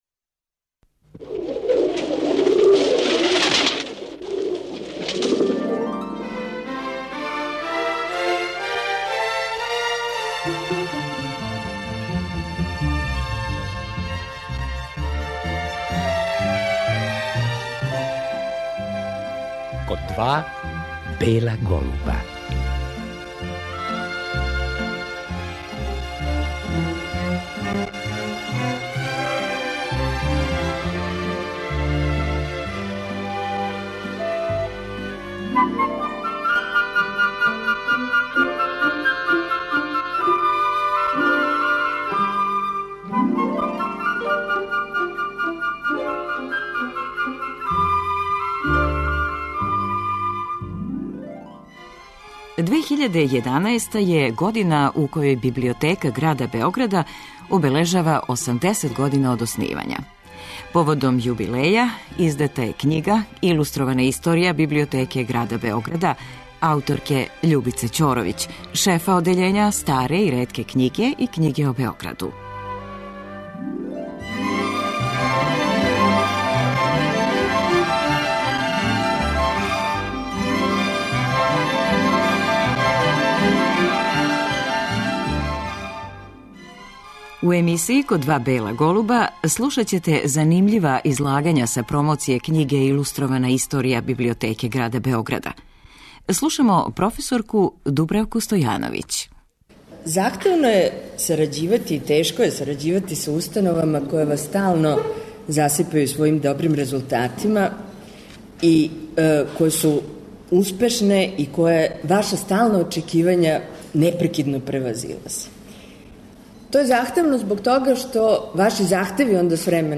Били смо на промоцији ове изузетно важне и занимљиве књиге и забележили излагања учесница